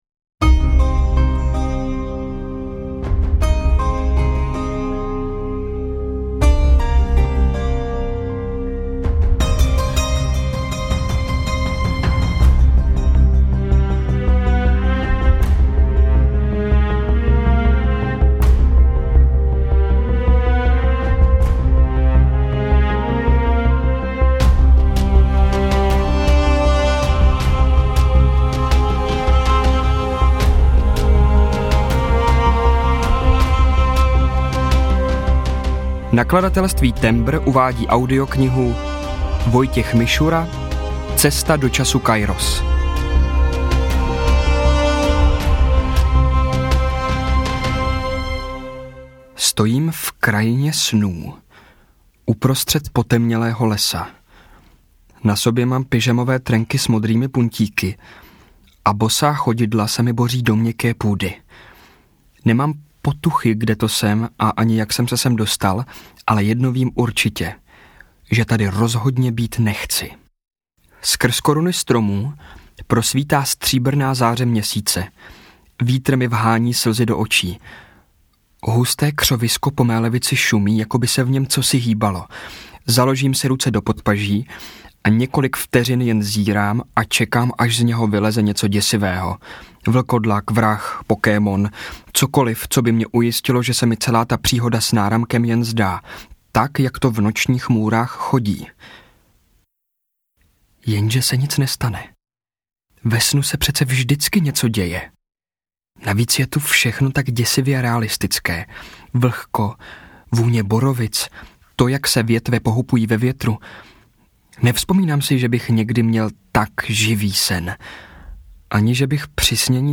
Cesta do času Kairos audiokniha
Ukázka z knihy